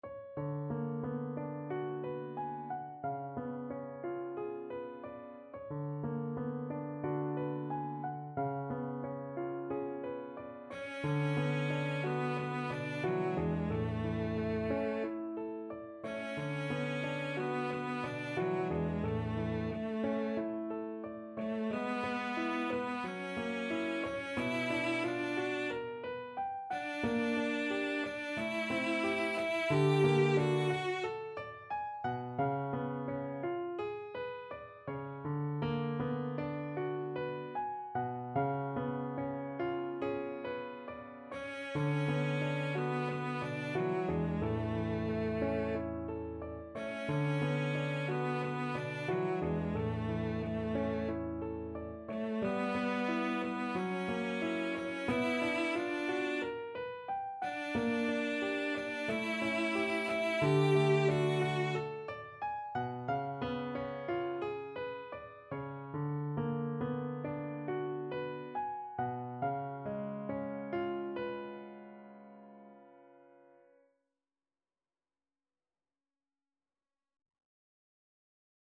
Cello version
Time Signature: 2/4
Tempo Marking: ~ = 45 Langsam, zart
Score Key: A major (Sounding Pitch)
Instrument: Cello
Style: Classical